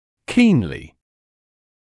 [‘kiːnlɪ][‘кинли]сильно, горячо